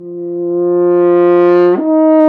Index of /90_sSampleCDs/Roland L-CDX-03 Disk 2/BRS_F.Horn FX/BRS_Intervals
BRS F HRN 0I.wav